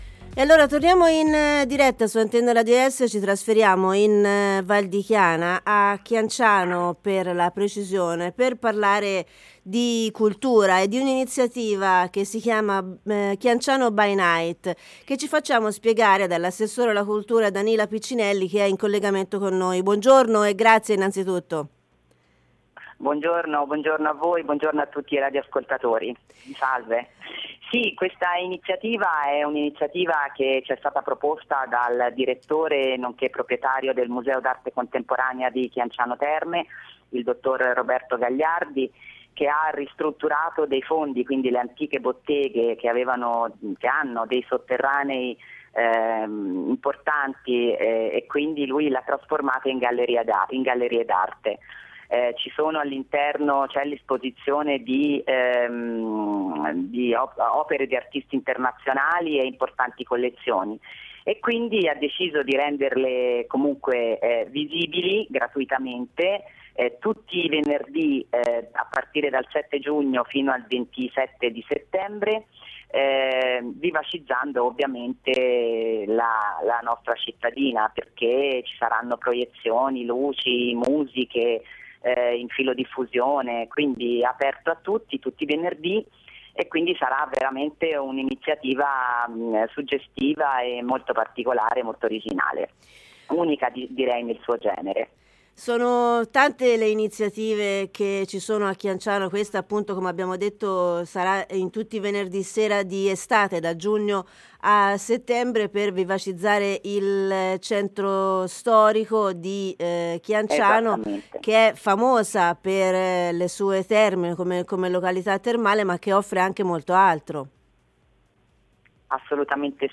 Interviste
Ne ha parlato ai nostri micrfofoni l’assessore alla cultura del comune di Chianciano Danila Piccinelli